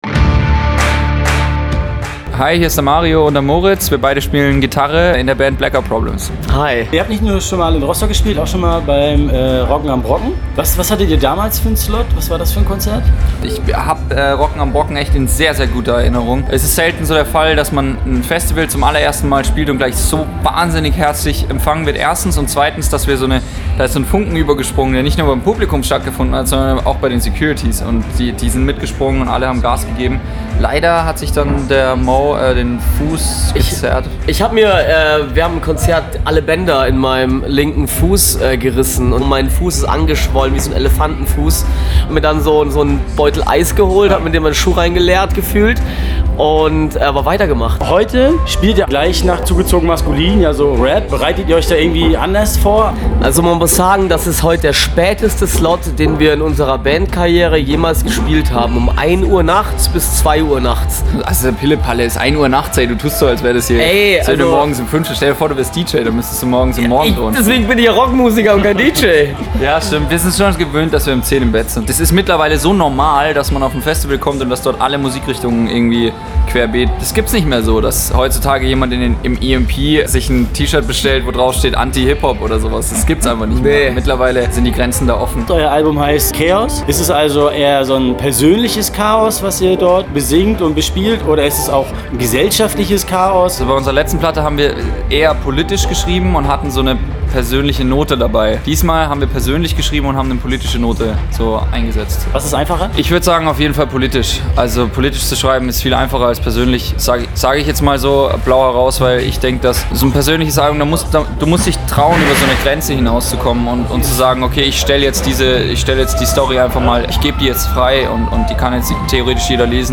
Blackout-Problems-Interview.mp3